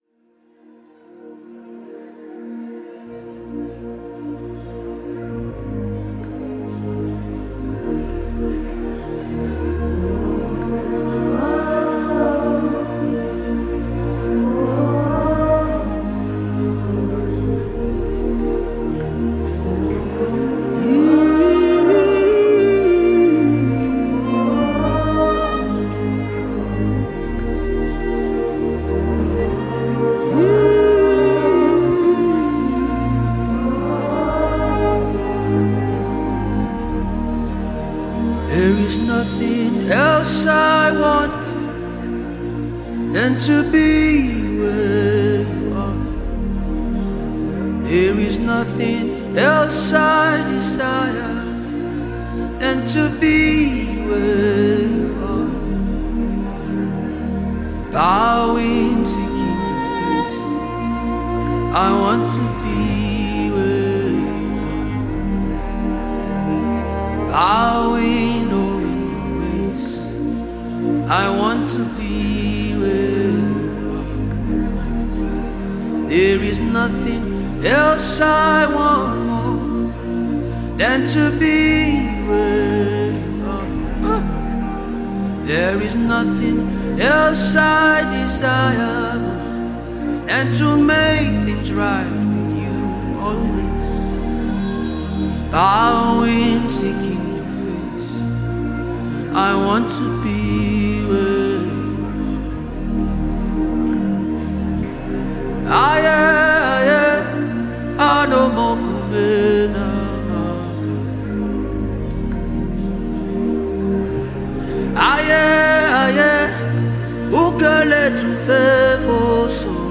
blends Afrobeat with contemporary sounds
With its upbeat tempo and catchy sounds